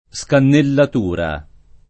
DOP: Dizionario di Ortografia e Pronunzia della lingua italiana
scannellatura [ S kannellat 2 ra ]